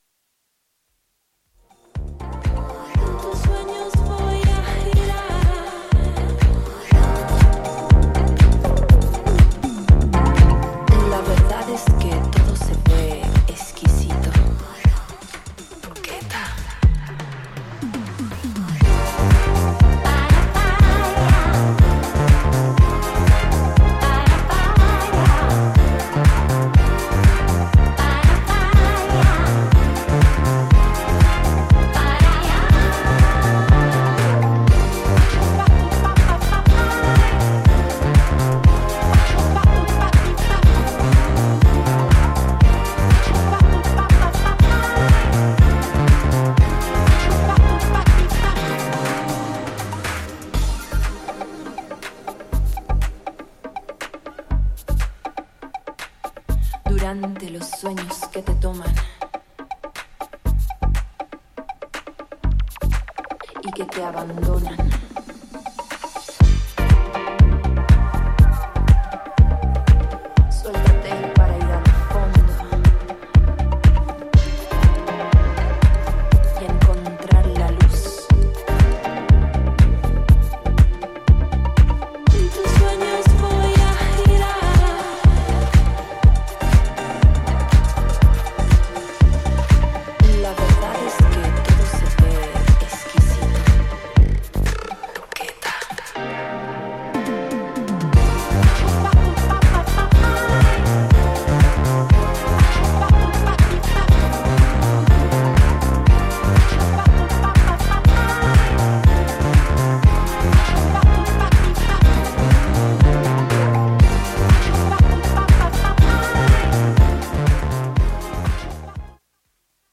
ディスコ/テック/バレアリックといったフロア受け抜群のハウスを展開するダンス・トラック全4曲を収録。
ジャンル(スタイル) HOUSE / DISCO HOUSE